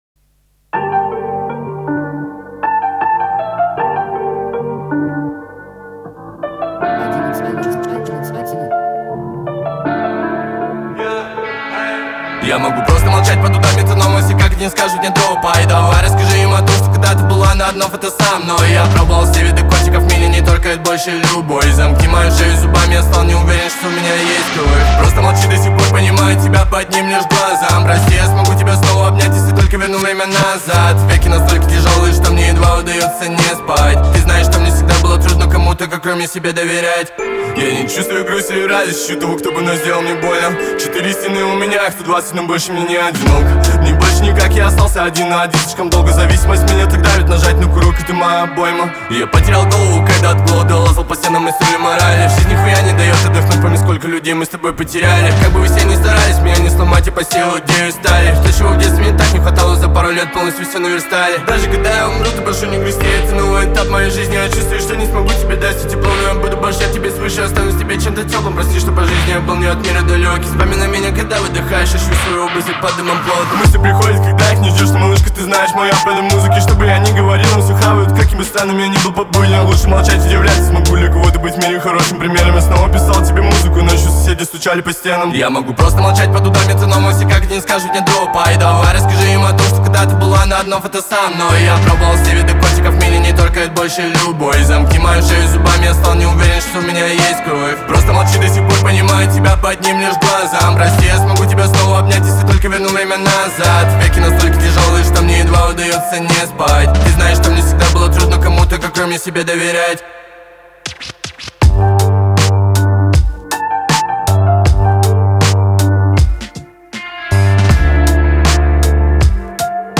Трек размещён в разделе Русские песни / Альтернатива / 2022.